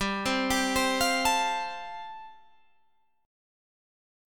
Listen to G7sus2sus4 strummed